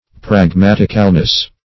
Search Result for " pragmaticalness" : The Collaborative International Dictionary of English v.0.48: Pragmaticalness \Prag*mat"ic*al*ness\, n. The quality or state of being pragmatical.